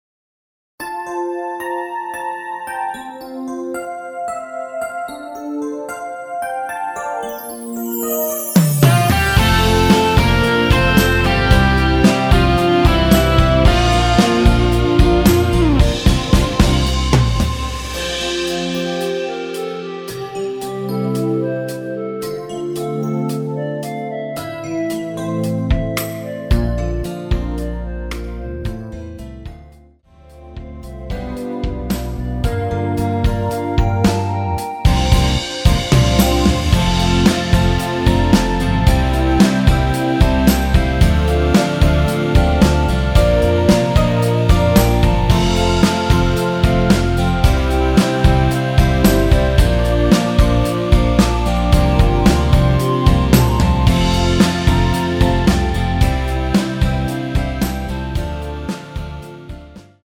원키에서(-2)내린 멜로디 포함된 MR입니다.
멜로디 MR이라고 합니다.
앞부분30초, 뒷부분30초씩 편집해서 올려 드리고 있습니다.